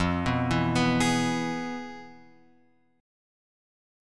F Minor Major 7th Flat 5th